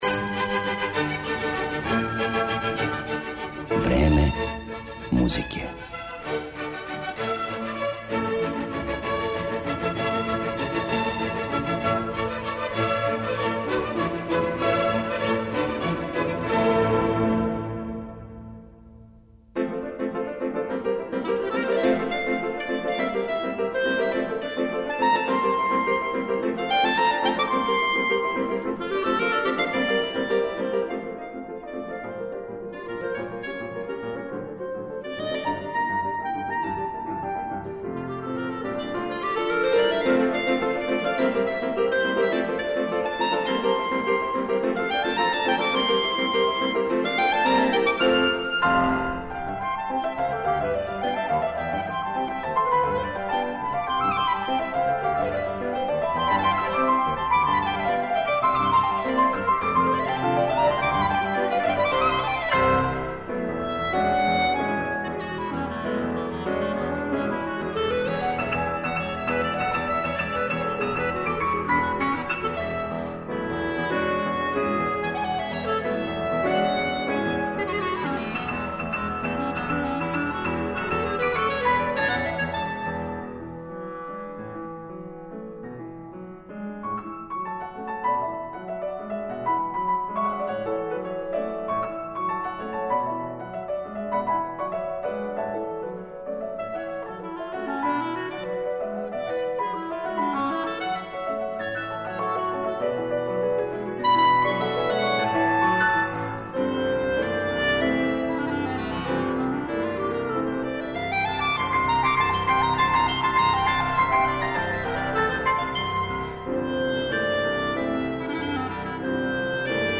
кларинет